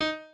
pianoadrib1_41.ogg